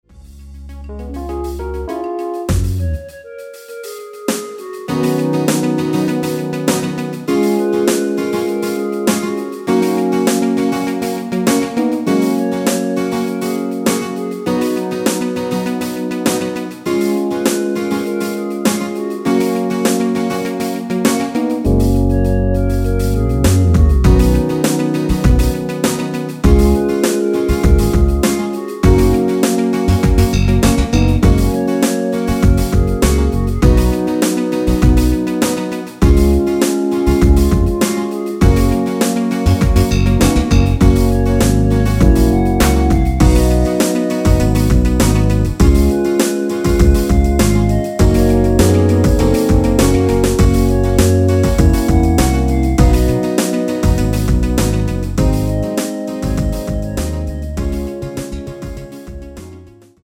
원키에서(-1)내린 멜로도 포함된 MR입니다.
F#
◈ 곡명 옆 (-1)은 반음 내림, (+1)은 반음 올림 입니다.
앞부분30초, 뒷부분30초씩 편집해서 올려 드리고 있습니다.
위처럼 미리듣기를 만들어서 그렇습니다.